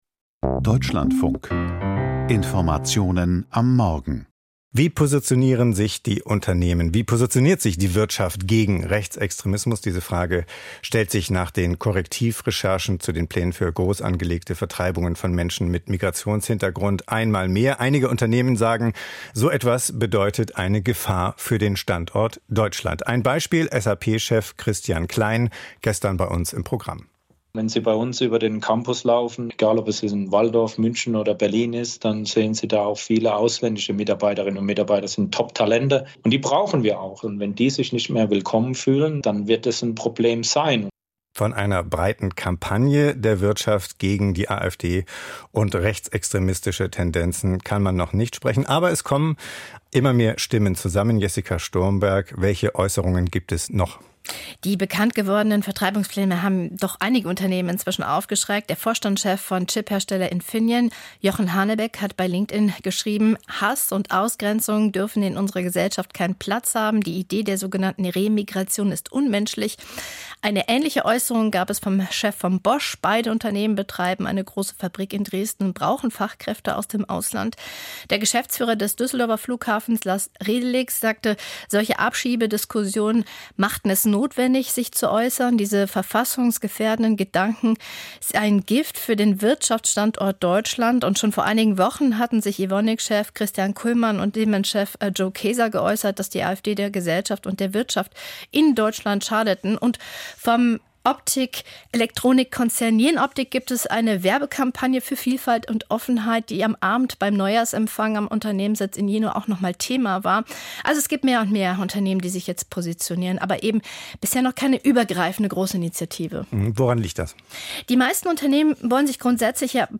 Das Wirtschaftsgespräch - Deutsche Unternehmen positionieren sich gegen Rechts